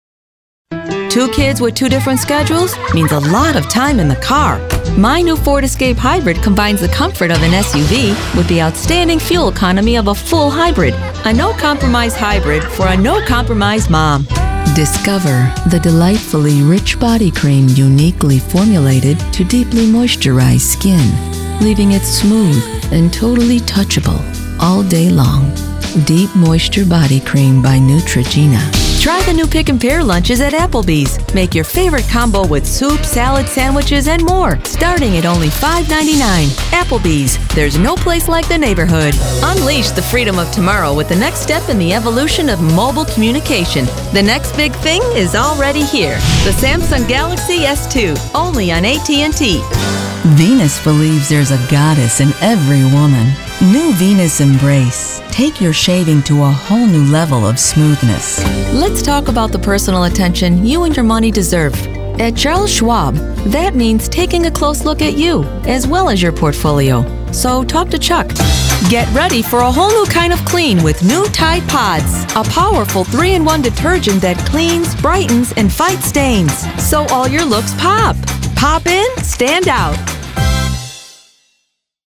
Her voice is natural, believable and engaging, perfect for a broad range of projects. Her voice is versatile, it can be friendly, fun, fresh, approachable, sincere, strong, intimate and sensual.
believable,honest,confident, warm,professional,smooth, sophisticated,fun,friendly, upbeat,trustworthy mom,sassy,sultry
middle west
Sprechprobe: Werbung (Muttersprache):